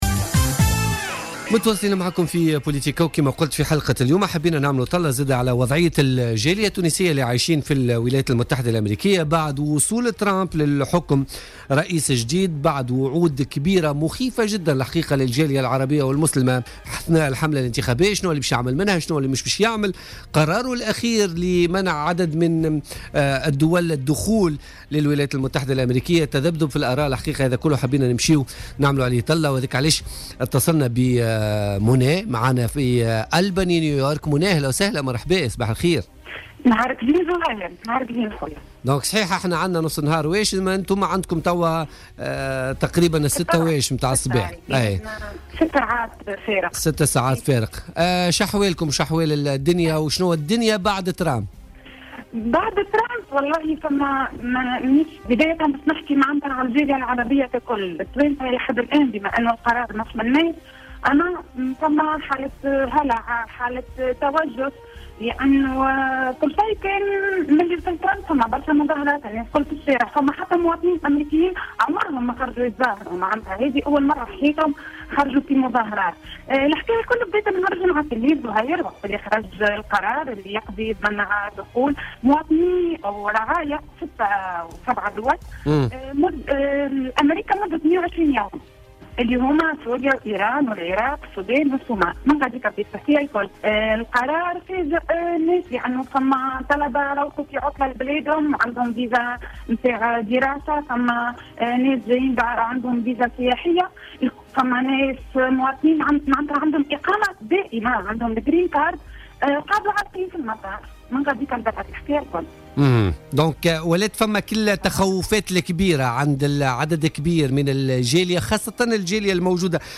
أكدت مواطنة تونسية مقيمة بولاية نيويورك الأمريكية في مداخلة لها في بولتيكا اليوم الثلاثاء 31 جانفي 2017 أن قرارات ترامب تجاه الجاليات العربية المسلمة في أمريكا أثارت حالة من الهلع والخوف في صفوف الجاليات عموما والتونسيين على وجه الخصوص رغم أن القرار لم يشملهم.